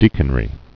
(dēkən-rē)